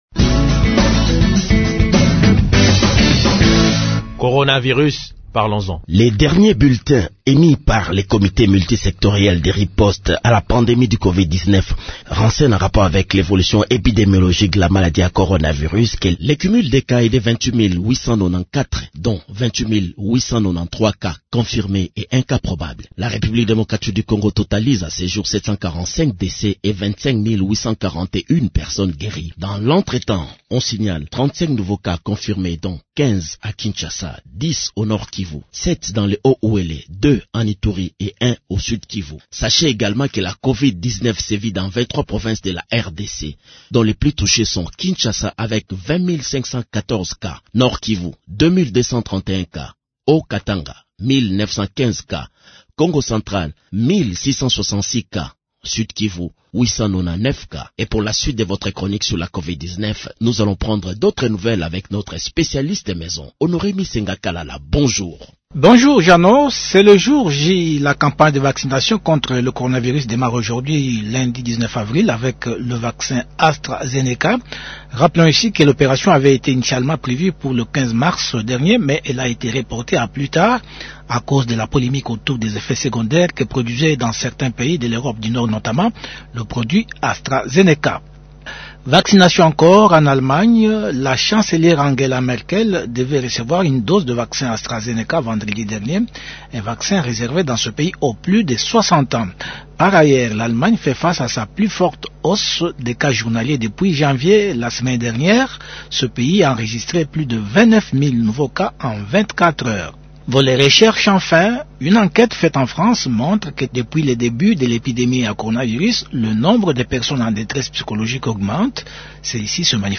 dont les propos en lingala sont ici traduits en français